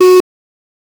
Add faint cloth movement for realism, quick decay, clean and grounded in a natural acoustic tone. 0:10 Short buzzer thud — low-mid synth beep with a slight distorted click, fast decay, dry and clearly indicates error 0:01 Glass marble bouncing on stone: bright sharp tinks, each bounce softer and closer together.
short-buzzer-thud--low-mi-lylbwwph.wav